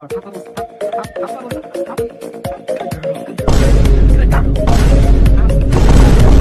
Sound Effects
Goofy Ahh Music Boom Boom